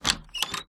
WoodenDoorSqueek
Tags: Dungeons and Dragons Sea Ship